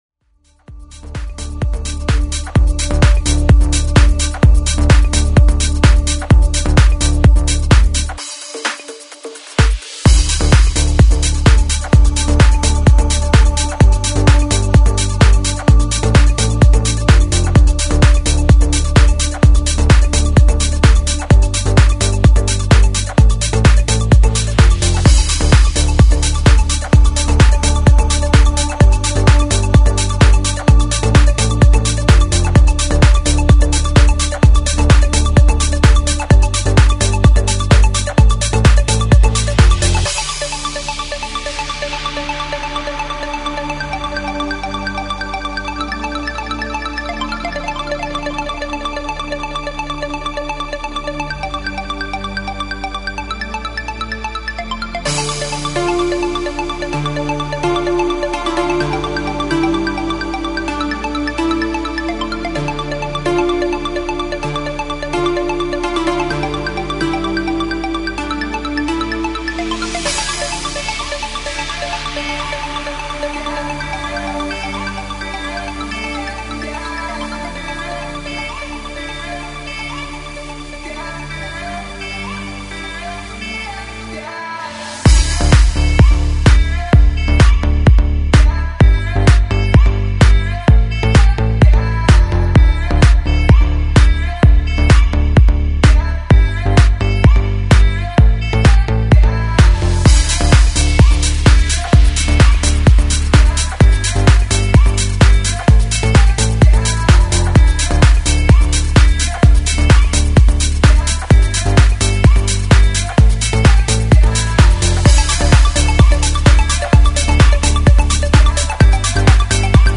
Progressive House